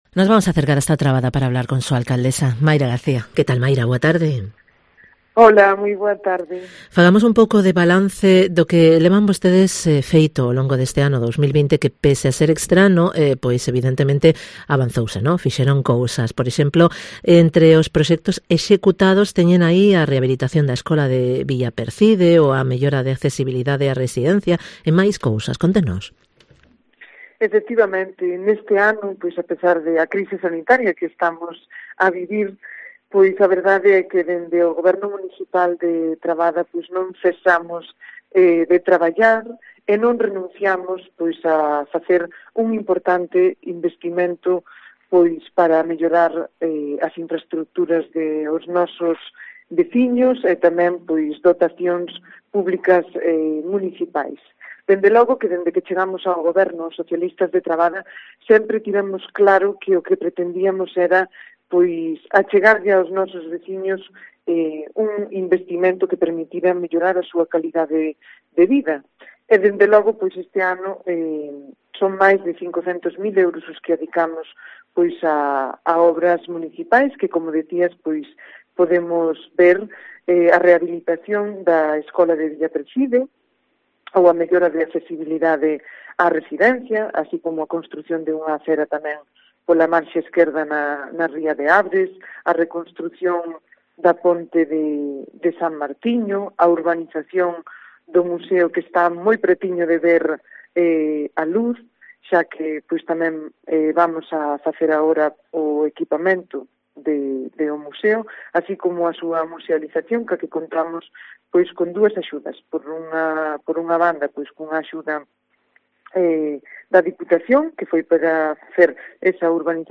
Entrevista con MAYRA GARCÍA, alcaldesa de Trabada